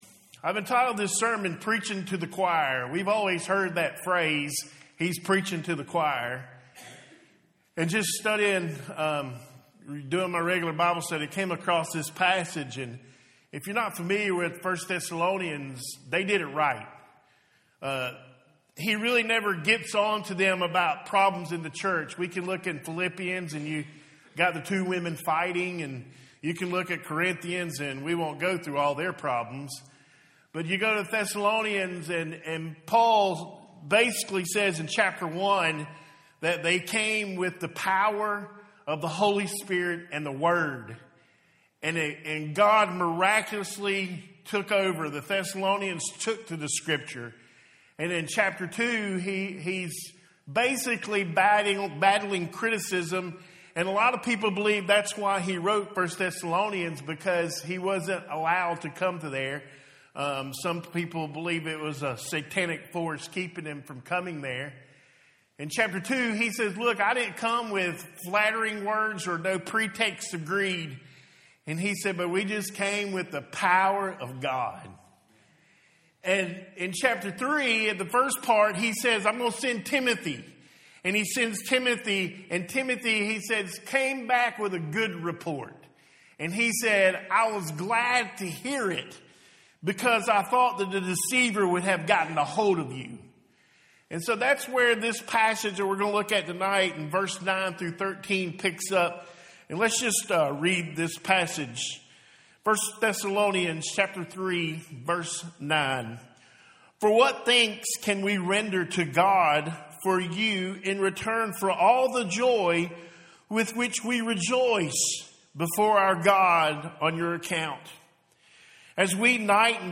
Home › Sermons › Preaching To The Choir